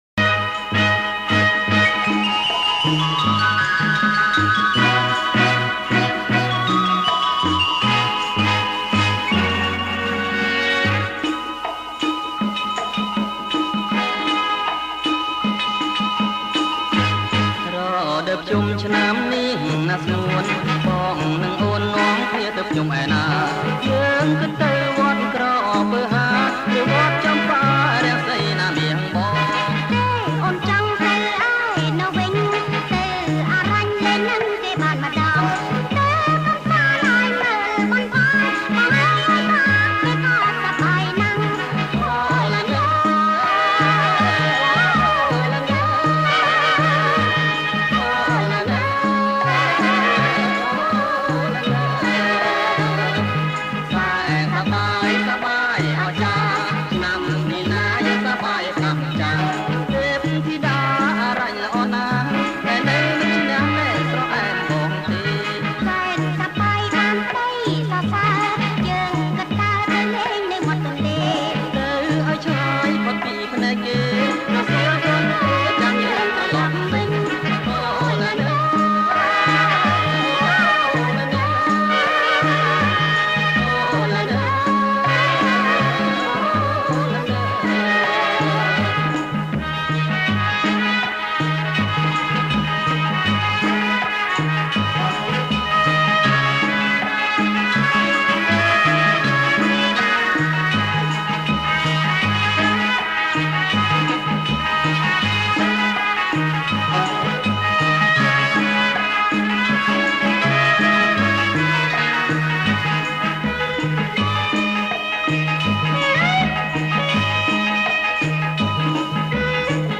• ប្រគំជាចង្វាក់ តាលុង